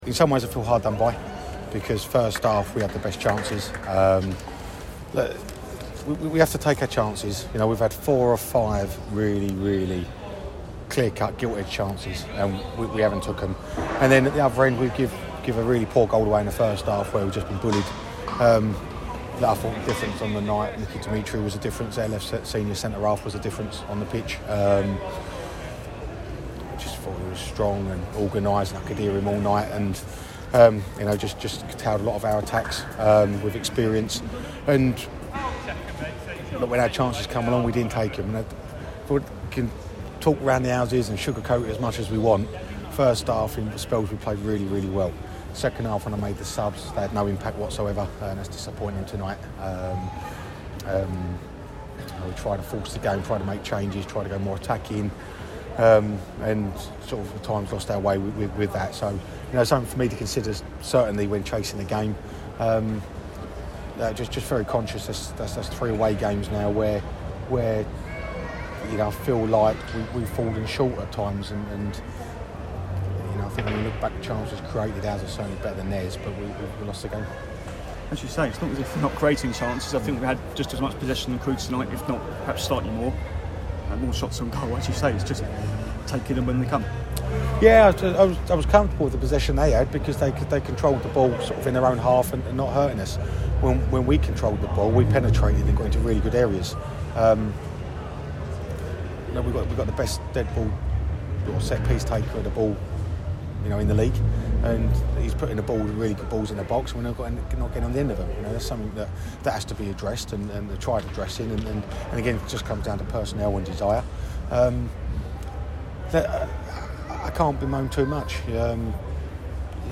spoke to reporters